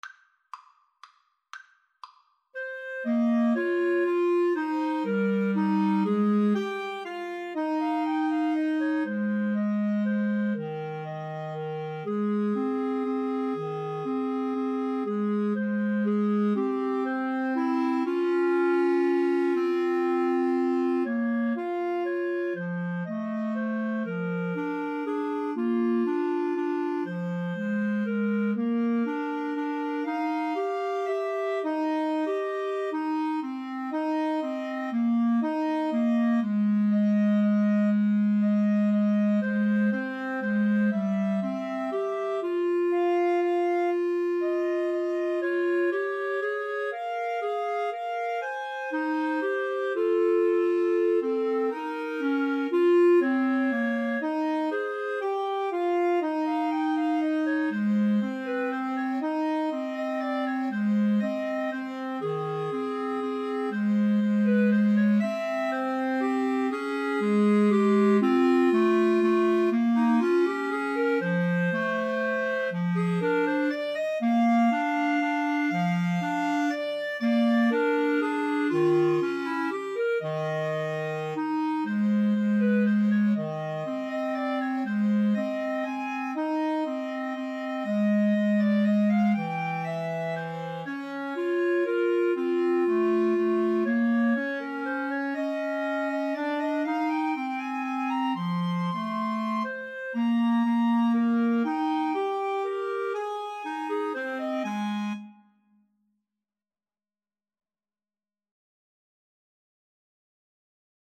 3/4 (View more 3/4 Music)
= 120 Tempo di Valse = c. 120
Jazz (View more Jazz Clarinet Trio Music)